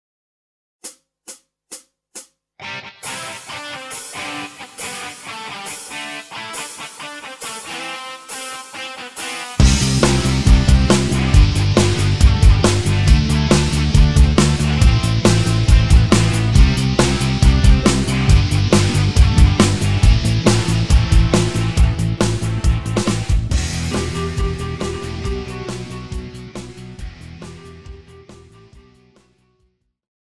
Žánr: Rock
Key:C
MP3 ukázka s melo. linkou